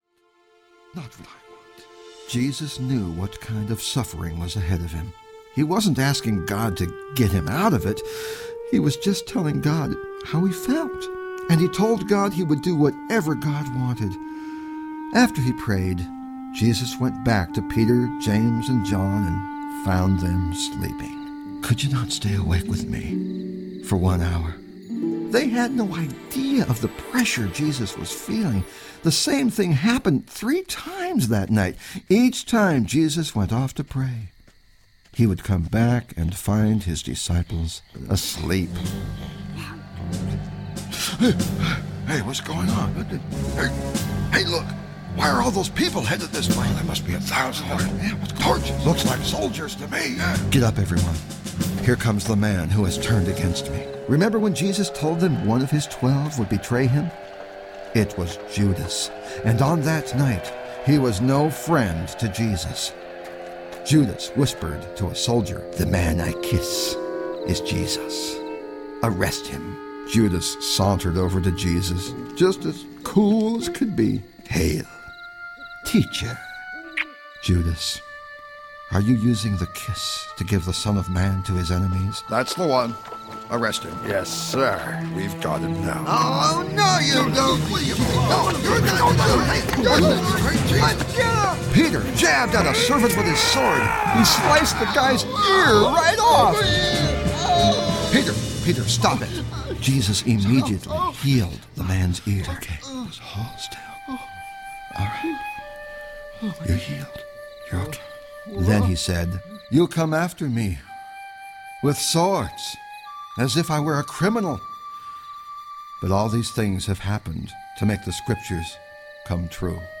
Frank Peretti is the ever-witty character Mr. Henry, telling some of the most popular and beloved stories from the Bible in his own unique style.
Narrator
Frank Peretti
0.67 Hrs. – Unabridged